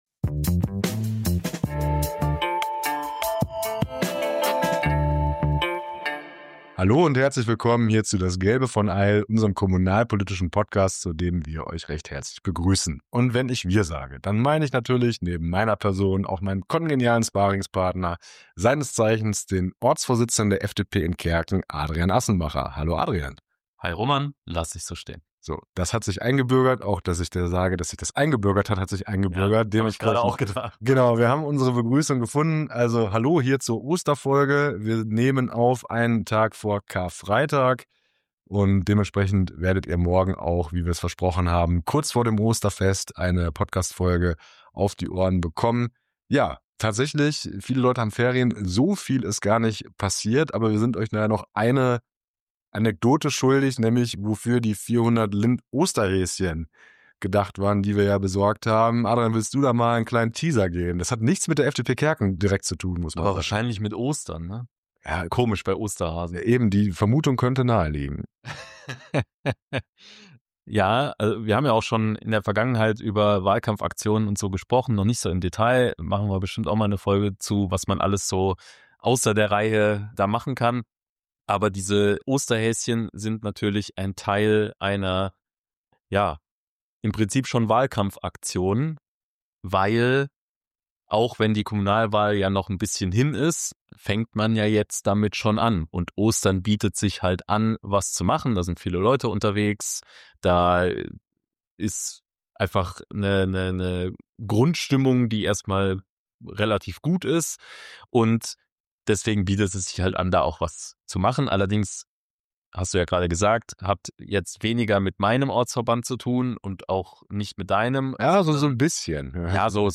Beschreibung vor 1 Jahr Frohe Ostern aus dem Studio!